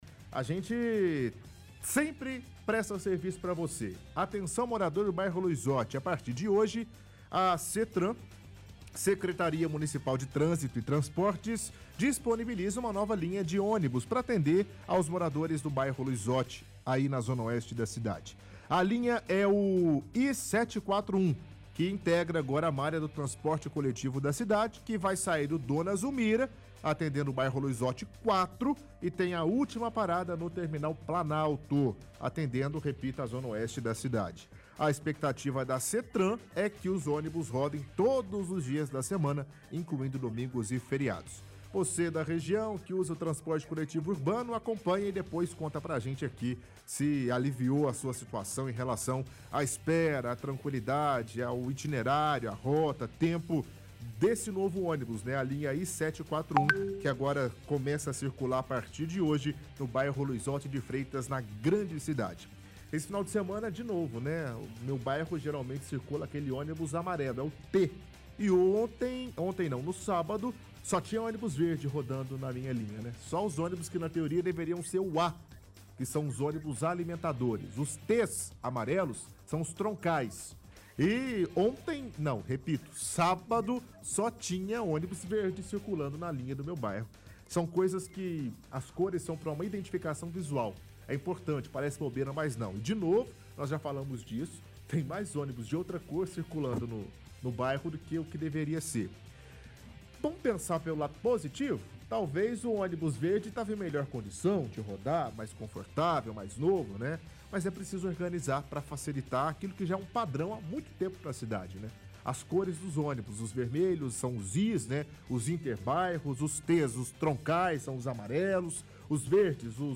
– Leitura de reportagem do site do Diário de Uberlândia.